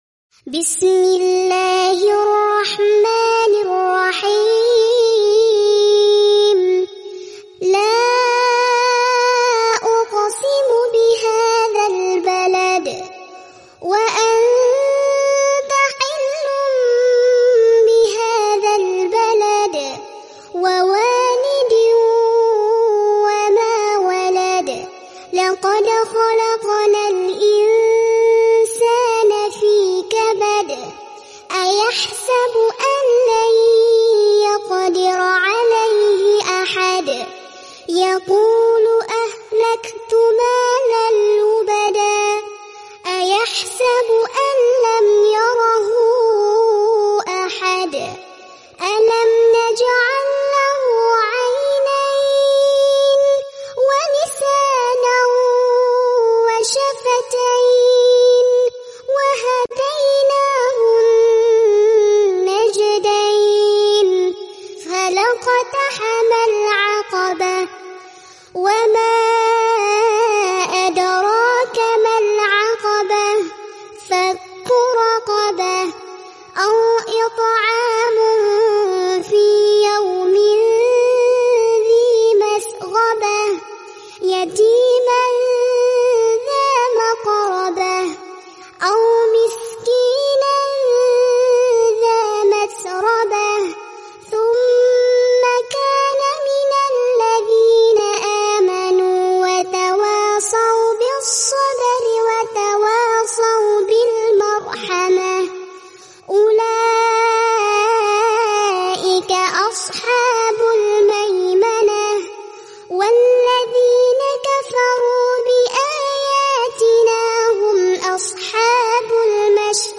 QS 90 AL BALAD Bayi Ngaji Murottal Anak Juz Amma.